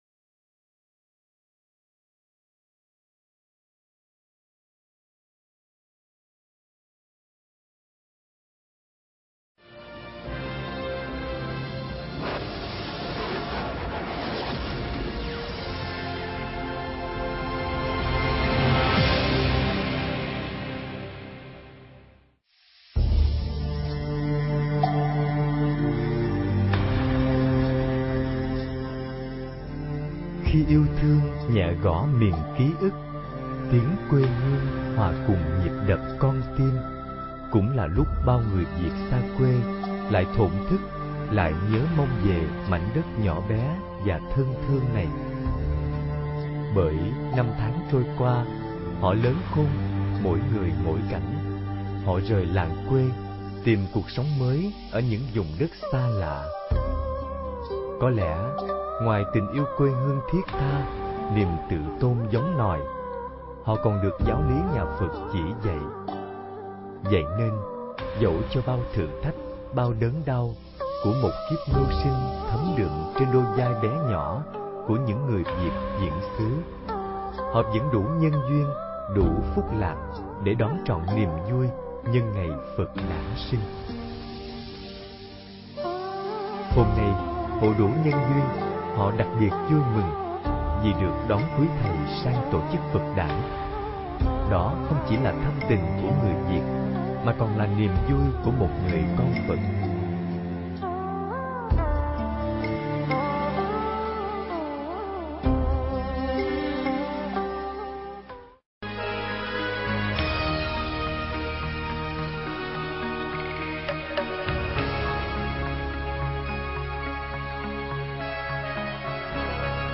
Nghe Mp3 thuyết pháp Sen Nở Trời Âu